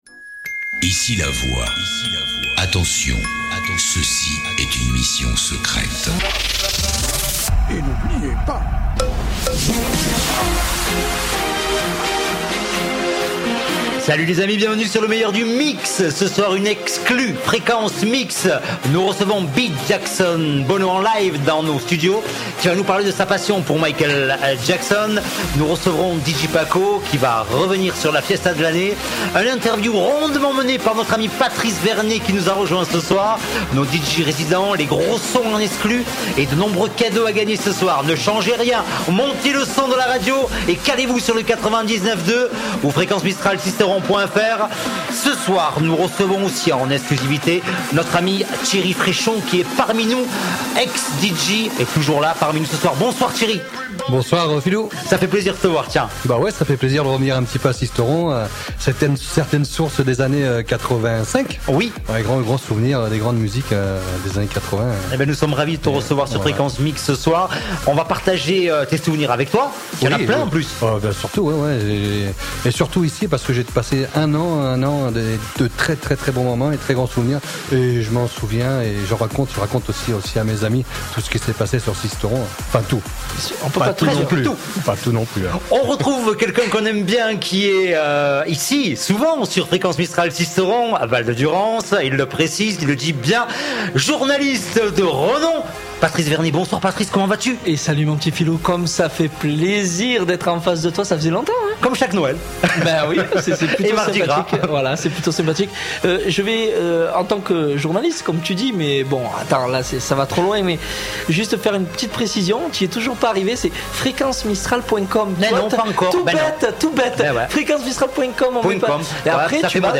Des DJ's du monde entier livrent leurs mix's dans cette émission unique en son genre.